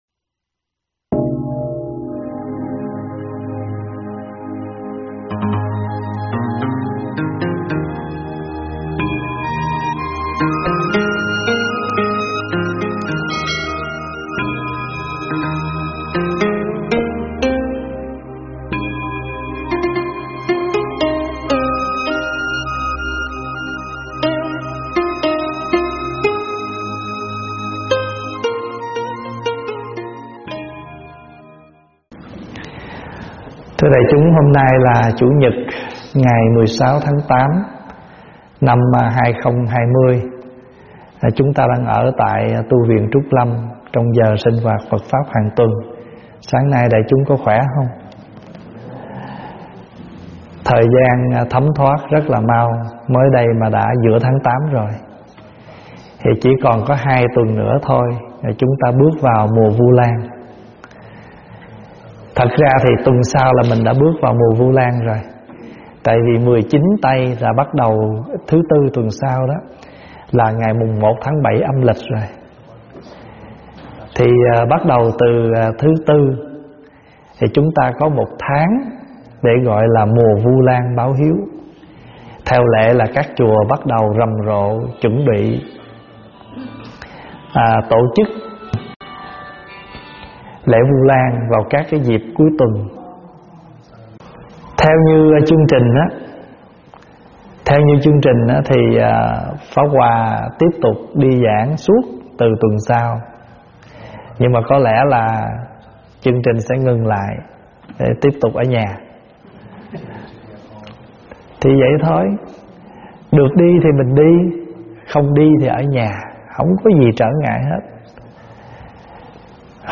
Nghe mp3 thuyết pháp Biết Chân Giả
giảng tại Tv Trúc Lâm